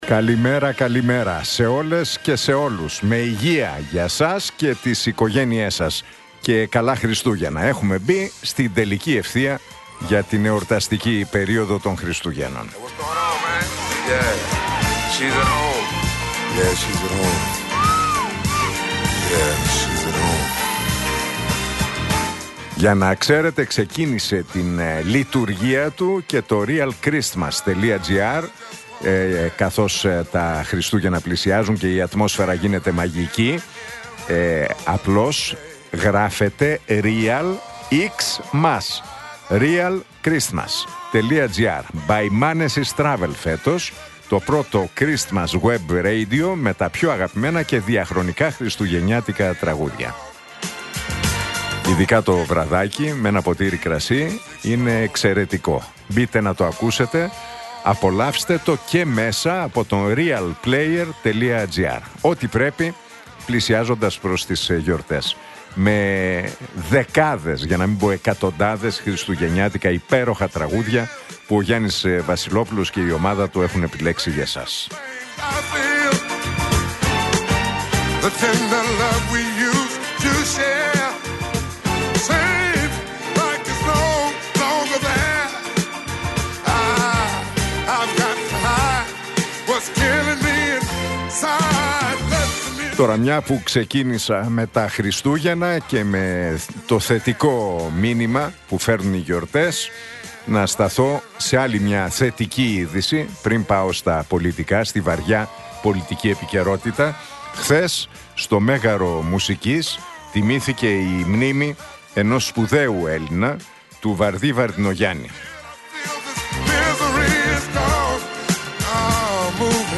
Ακούστε το σχόλιο του Νίκου Χατζηνικολάου στον ραδιοφωνικό σταθμό Realfm 97,8, την Τετάρτη 26 Νοεμβρίου 2025.